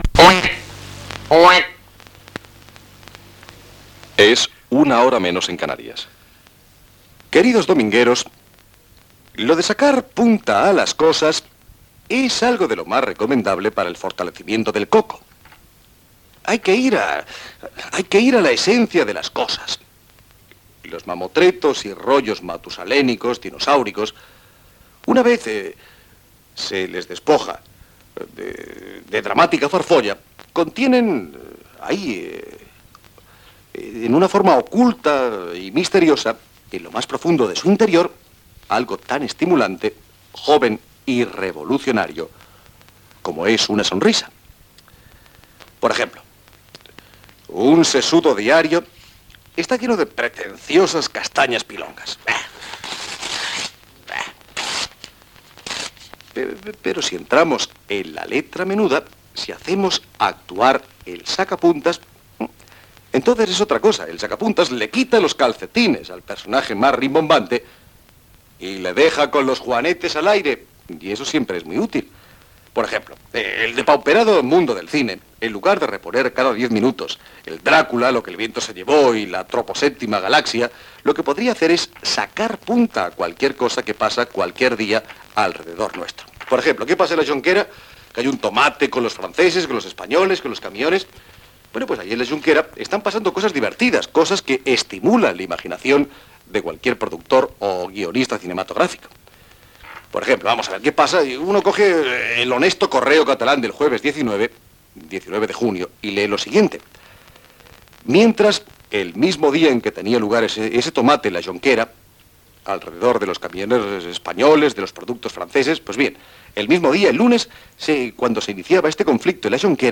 entrada del programa amb diferents ambientacions musicals
Gènere radiofònic Entreteniment